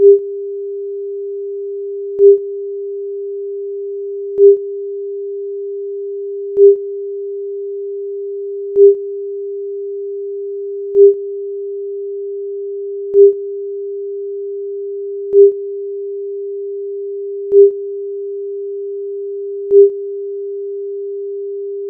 The new version consists of a 400 Hz sine at -20 dBFS, with an embedded sine burst at 0 dBFS (two-second duty cycle).